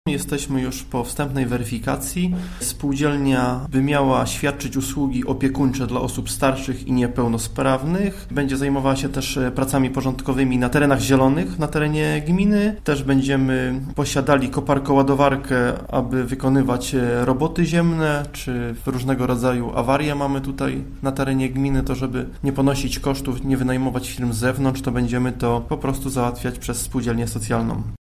– Jesteśmy na etapie organizacyjnym, ale z pewnością wkrótce taka spółdzielnia u nas powstanie – informuje Marcin Reczuch, wójt gminy Dąbie: